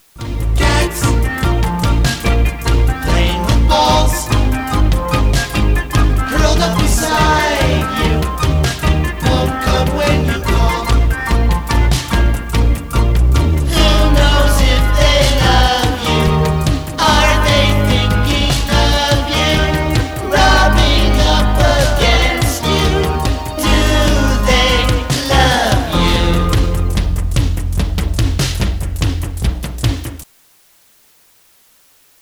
A 30 second low quality sample of this track: